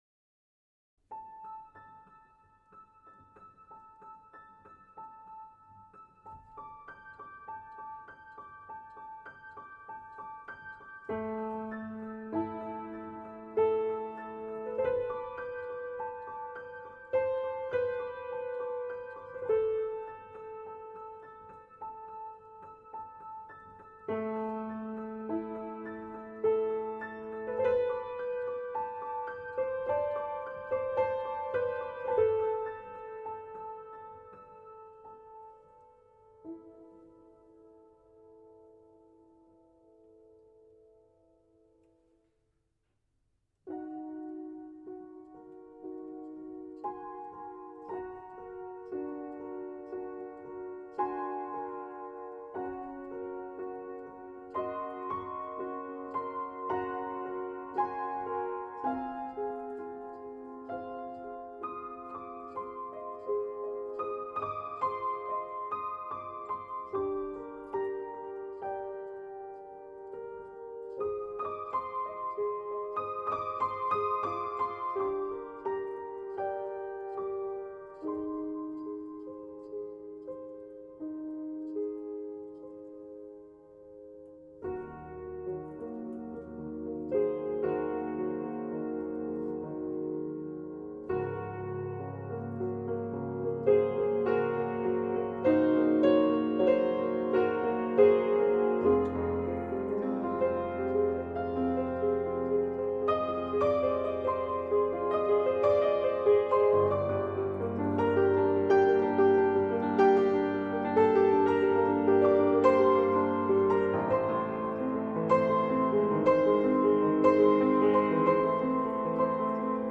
Super morceau, très bon jeu, léger et prenant à la fois...